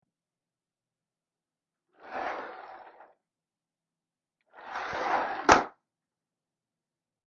办公场所的噪音 " 滑动的门
描述：只是一扇推拉门
Tag: 滑板 大声